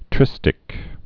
(trĭstĭk)